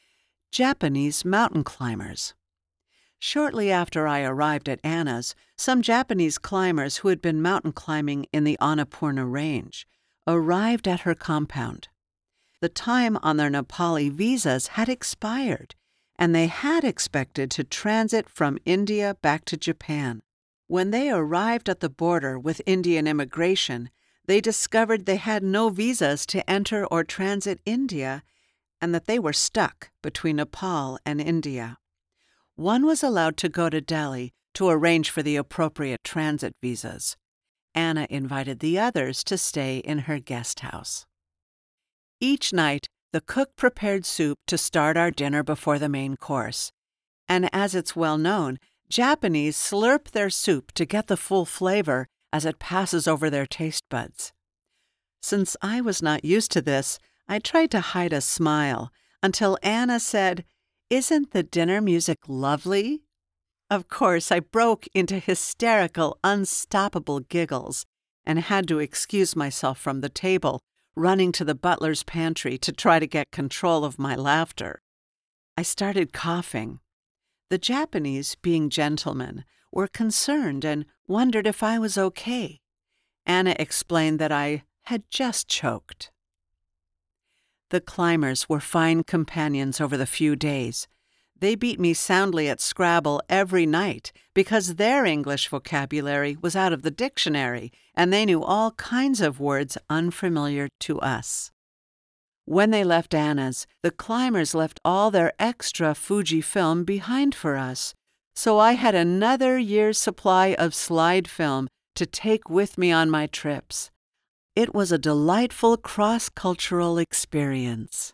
TZ Stellar X2  mic
Audiobook Memoir Narration:
Narration Voice age: Adult, 40-50s
Calm
Warm
audiobook-memoir-demo.mp3